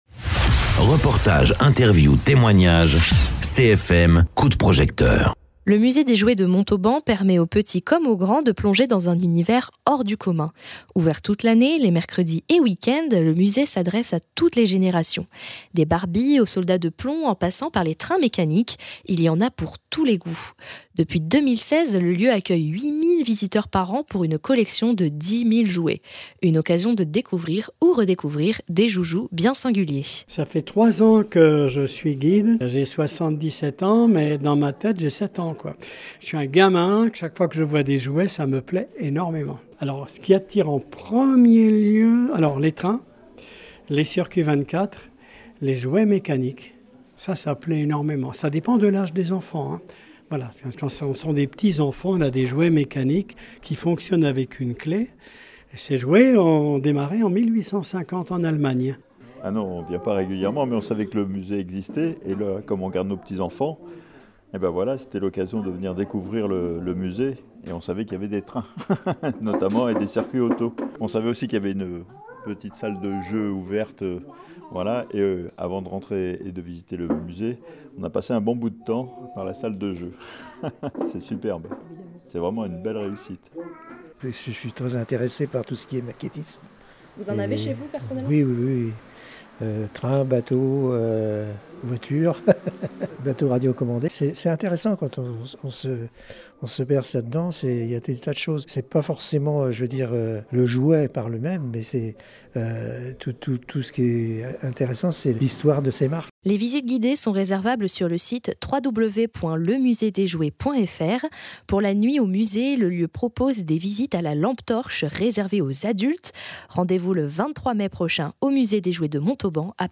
Interviews
Invité(s) : Micro trottoir dans le musée des jouets de Montauban